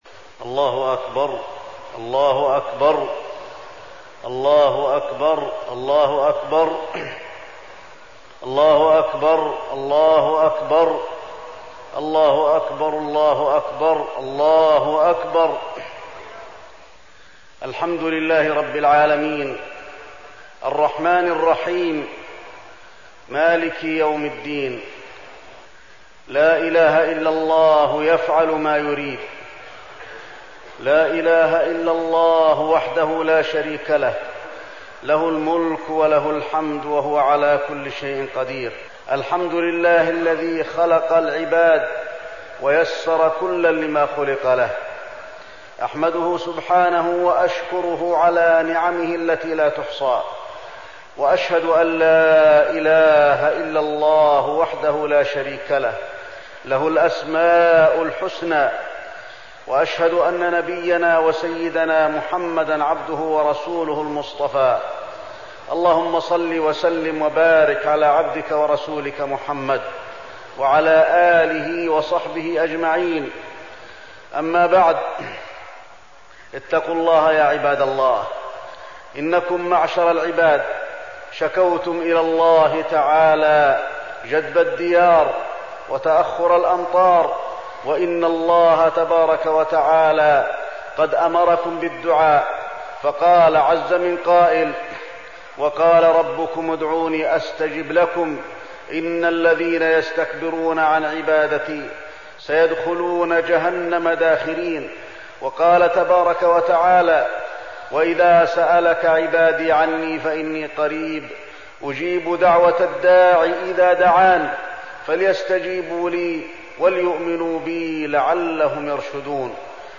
خطبة الخسوف المدينة - الشيخ علي الحذيفي - الموقع الرسمي لرئاسة الشؤون الدينية بالمسجد النبوي والمسجد الحرام
تاريخ النشر ١٥ جمادى الأولى ١٤١٨ هـ المكان: المسجد النبوي الشيخ: فضيلة الشيخ د. علي بن عبدالرحمن الحذيفي فضيلة الشيخ د. علي بن عبدالرحمن الحذيفي خطبة الخسوف المدينة - الشيخ علي الحذيفي